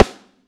high damp snare ff.wav